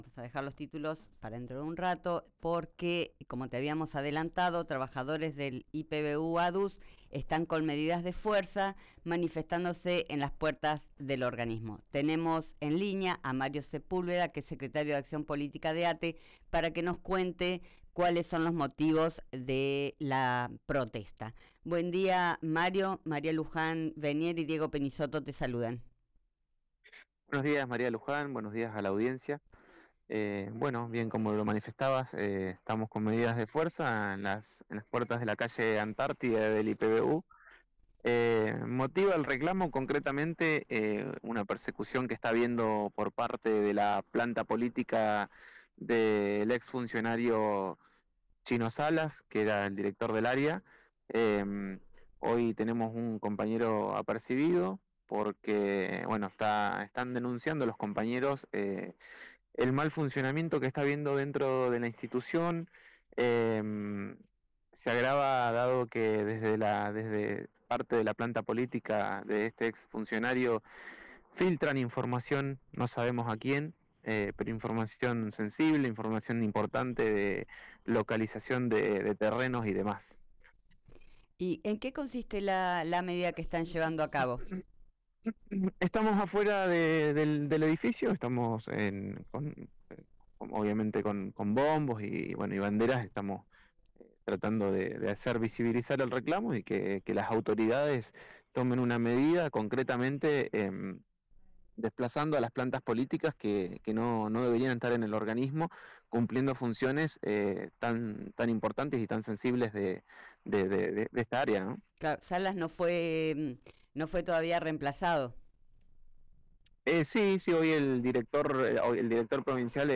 En diálogo con RÍO NEGRO RADIO explicó que la medida no implicó corte de calle, pero sí «sacaron los bombos» para visibilizar el reclamo.